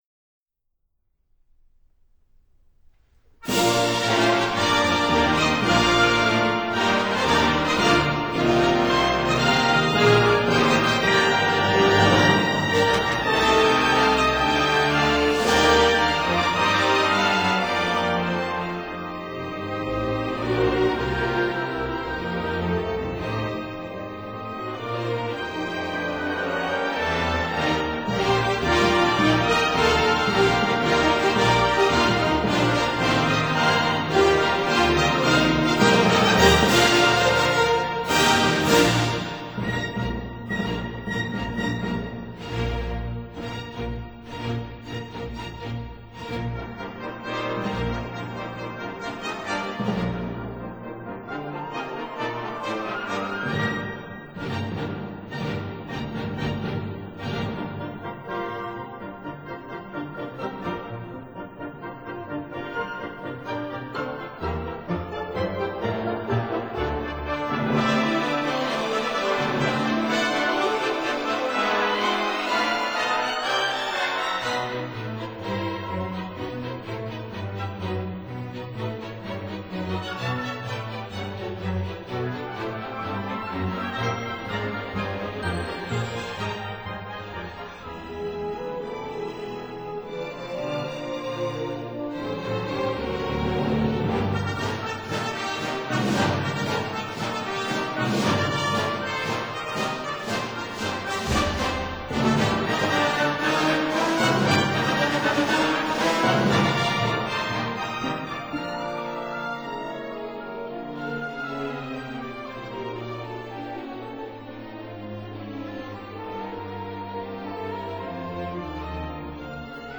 cor anglais
clarinet
horn